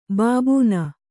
♪ bābūna